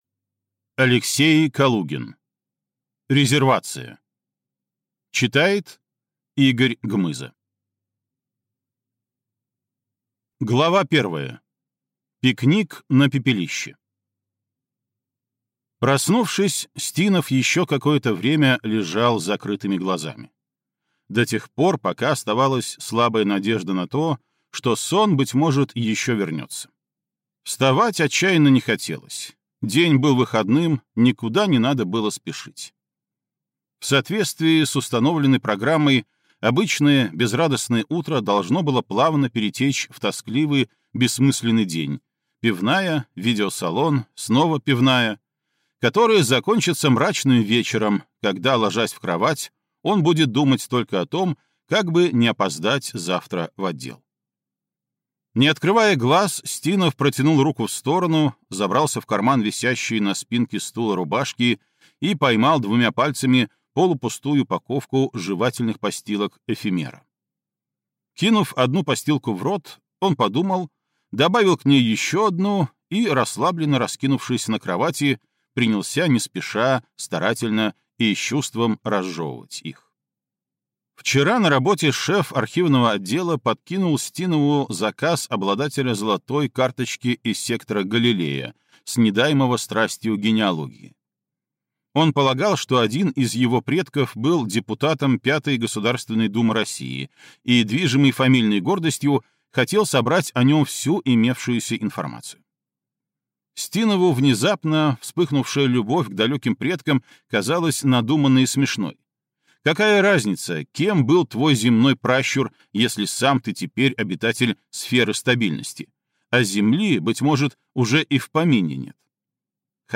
Аудиокнига Резервация | Библиотека аудиокниг